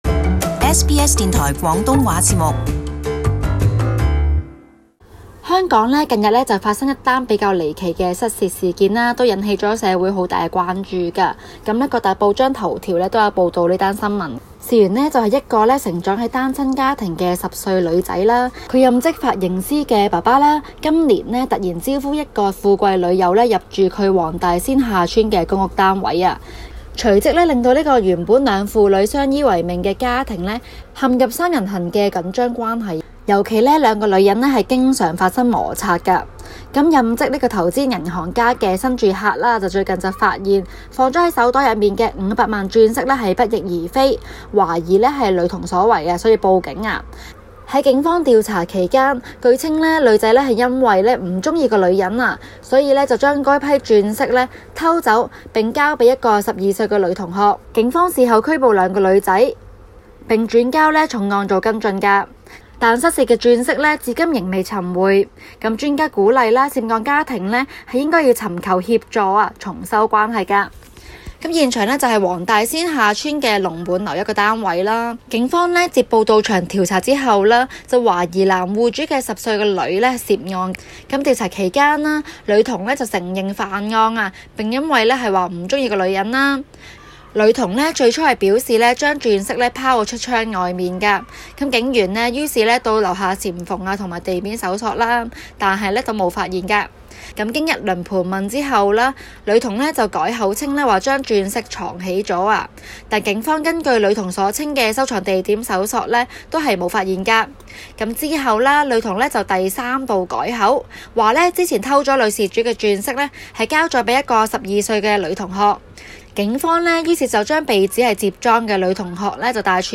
【中港快訊】香港公屋五百萬鑽飾偷竊案惹社會關注